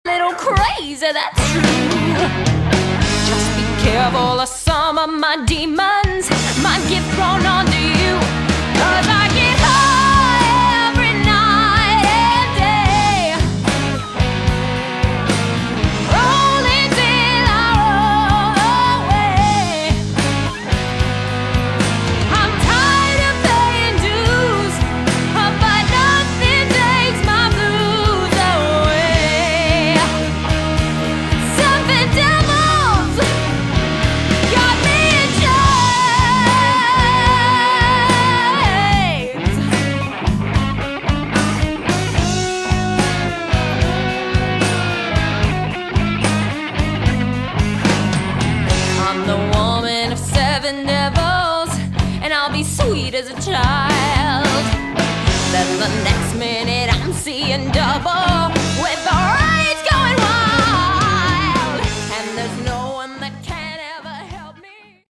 Category: Prog / AOR
keyboards, guitar
bass
drums